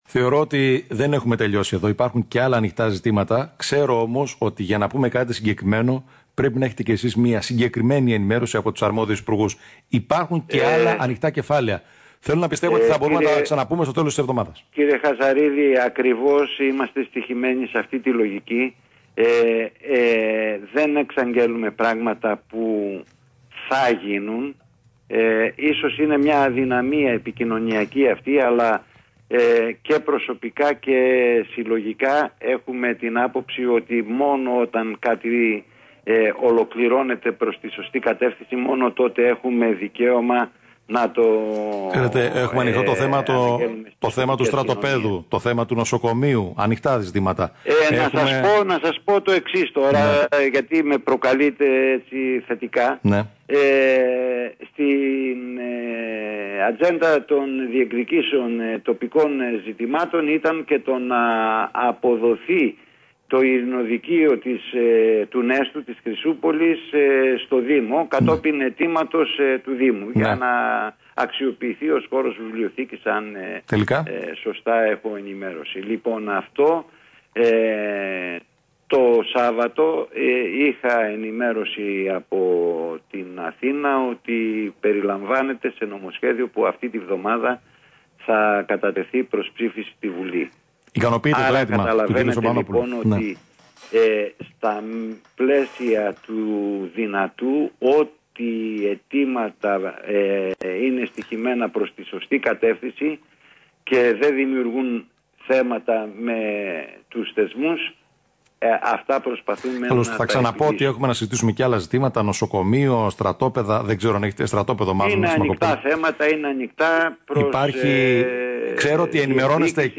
Ακούστε τη δήλωση Εμμανουηλίδη εδώ: